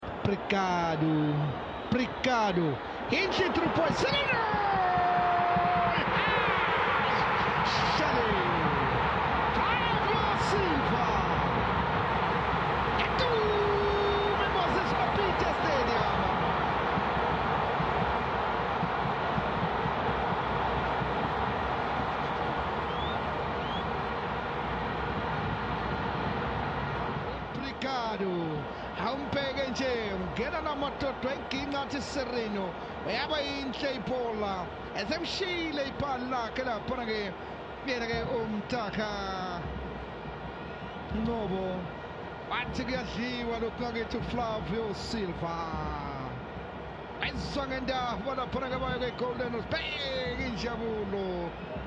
Listen to the noise in Moses Mabhida as Chiefs open the scoring